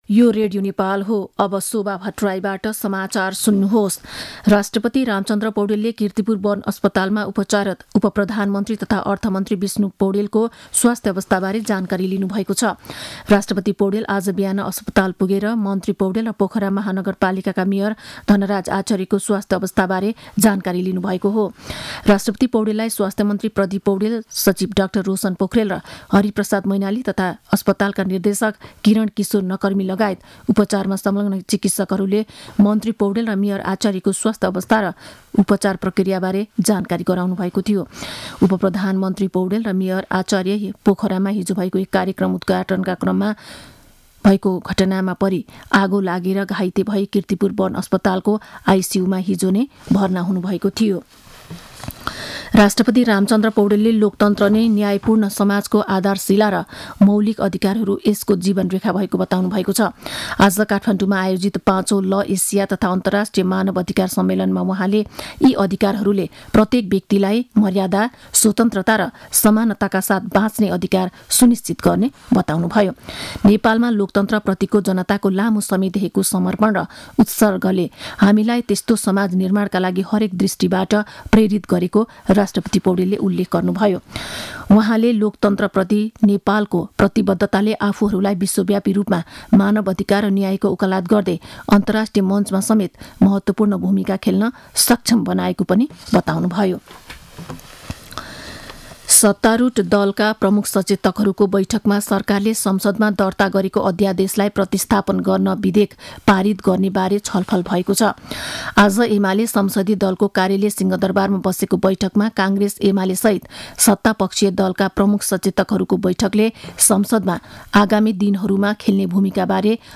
मध्यान्ह १२ बजेको नेपाली समाचार : ५ फागुन , २०८१